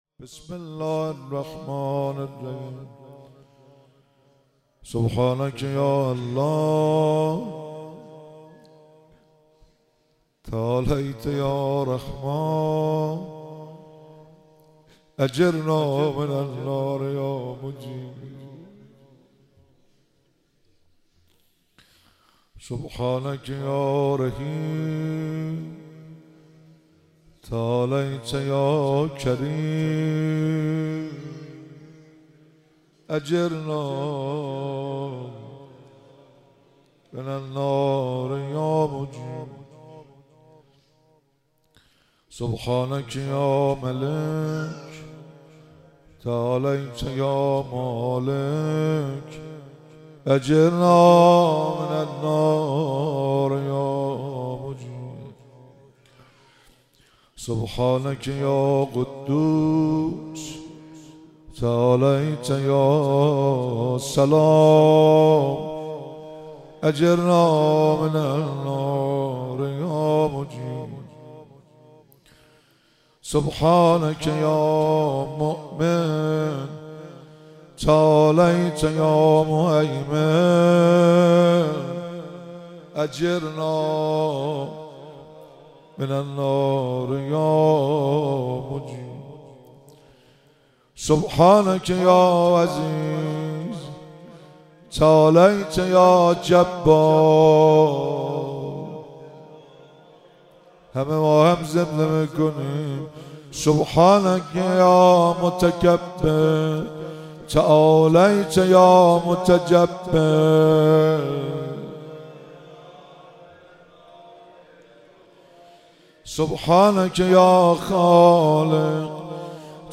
مراسم مناجات خوانی شب چهاردهم ماه رمضان 1444